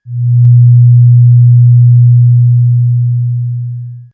BadTransmission6.wav